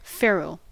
Ääntäminen
Ääntäminen US Haettu sana löytyi näillä lähdekielillä: englanti Käännös Substantiivit 1. casquillo {m} Määritelmät Substantiivit A metal band or cap placed around a shaft to reinforce it or to prevent splitting .